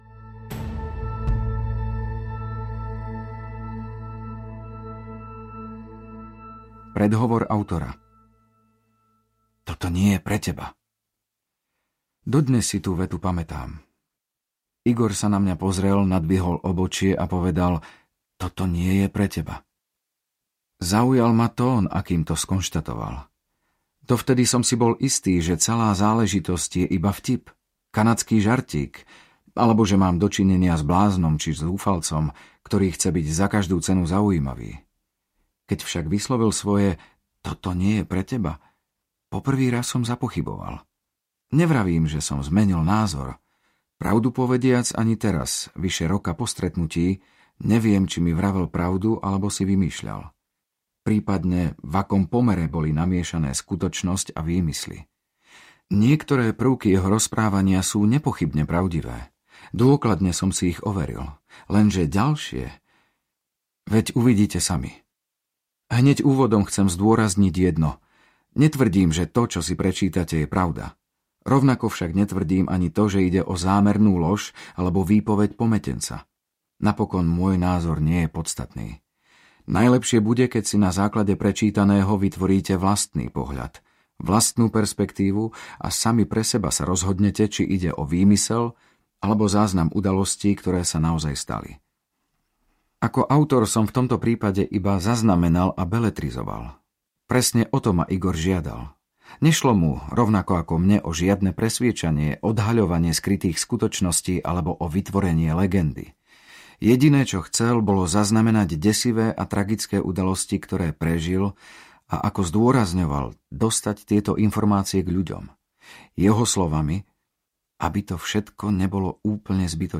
Trhlina audiokniha
Ukázka z knihy